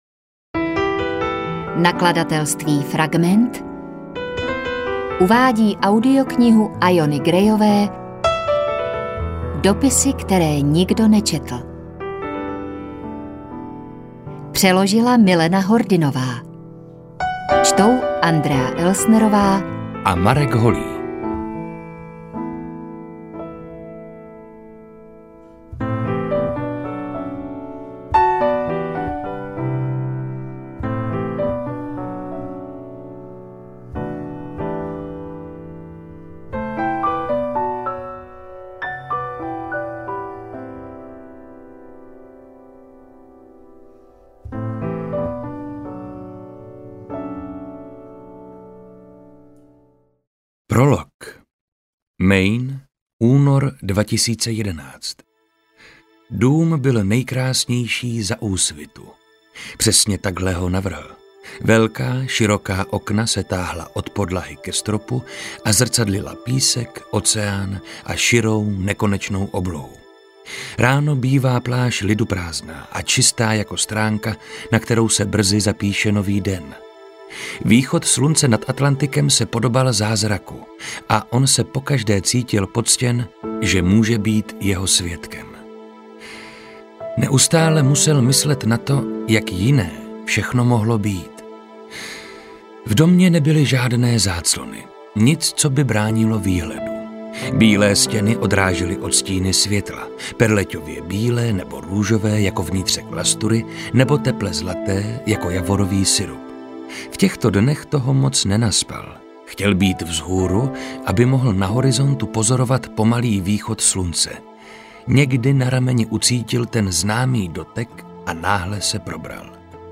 Poslouchala jsem jako audioknihu načtenou Markem Holým a Andreou Elsnerovou.
Jako audiokniha výborně namluveno.
AudioKniha ke stažení, 43 x mp3, délka 15 hod. 7 min., velikost 826,8 MB, česky